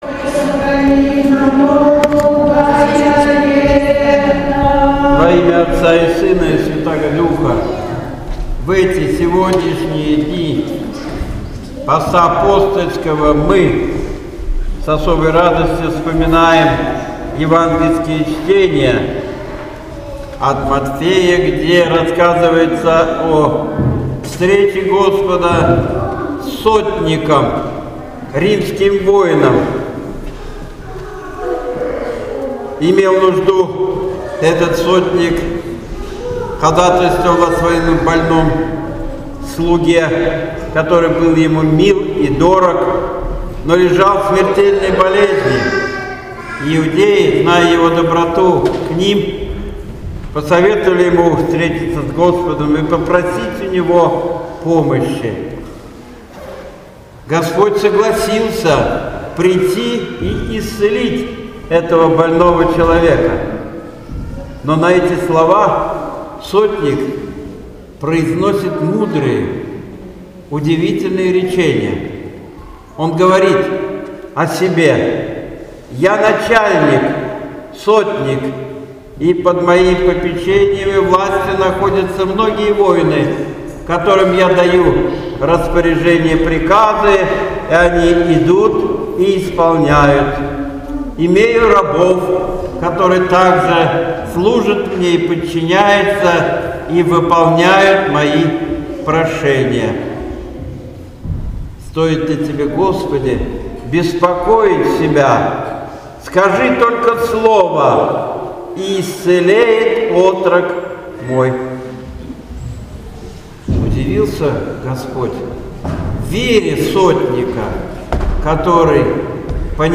Божественная Литургия в Неделю 3-ю по Пятидесятнице